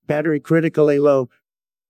battery-critically-low.wav